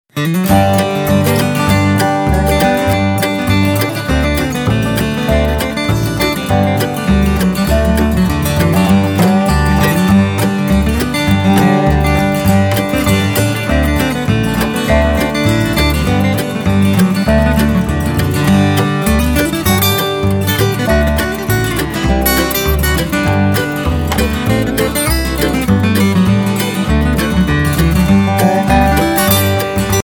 An album of Swedish Bluegrass music.